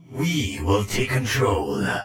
OC Puppeteer Voice Over